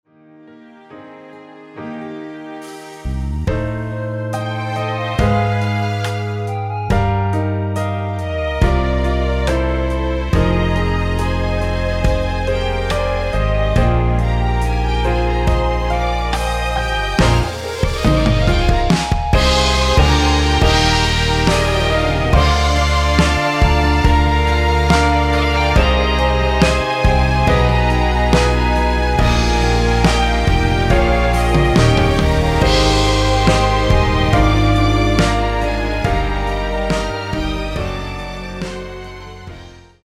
원키에서(+5)올린 멜로디 포함된(1절앞 + 후렴)으로 진행되는 MR입니다.
Eb
노래방에서 노래를 부르실때 노래 부분에 가이드 멜로디가 따라 나와서
앞부분30초, 뒷부분30초씩 편집해서 올려 드리고 있습니다.
중간에 음이 끈어지고 다시 나오는 이유는